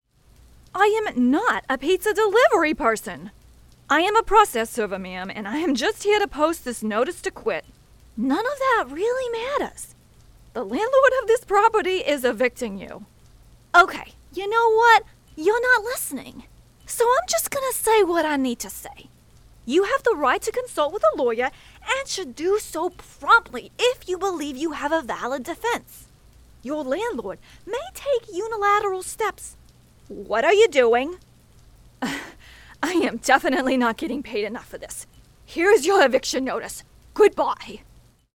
Super Suits - Process Server (New York)